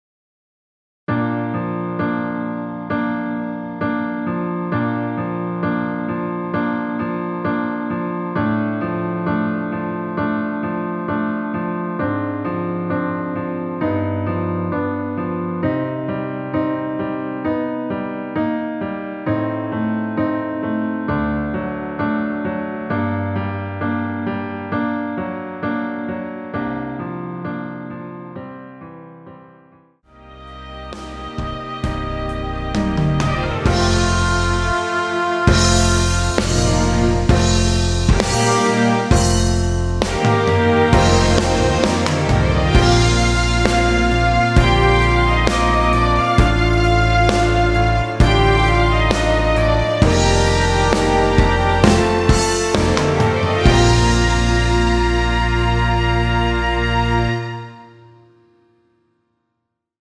전주 없이 시작하는 곡이라 전주 1마디 만들어 놓았으며
원키에서(-2)내린 MR입니다.
Bb
앞부분30초, 뒷부분30초씩 편집해서 올려 드리고 있습니다.
중간에 음이 끈어지고 다시 나오는 이유는